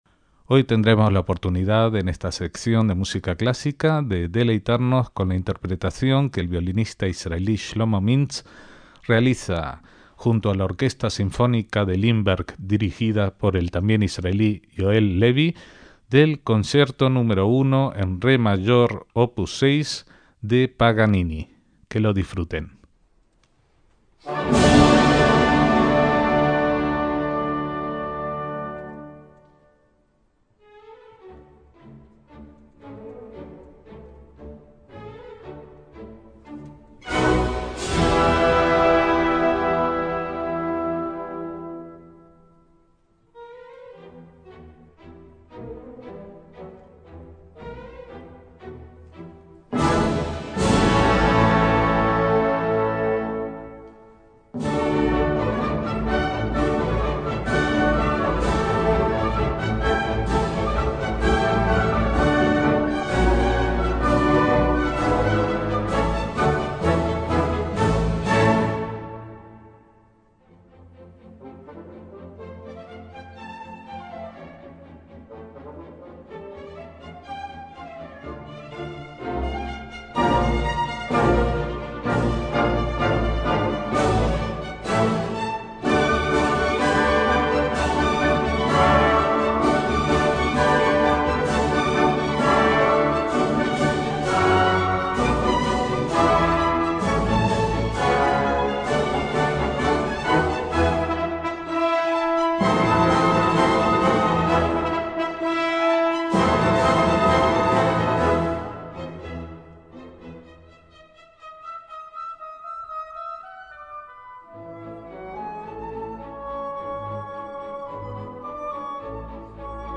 Shlomo Mintz toca el Concierto nº 1 de Paganini, bajo la batuta de Yoel Levi